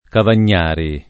Cavagnari [ kavan’n’ # ri ] cogn.